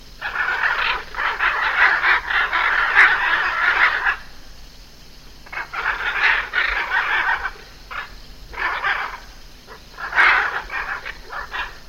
macaw-ringtone.mp3